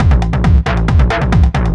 DS 136-BPM A7.wav